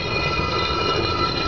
movestone.wav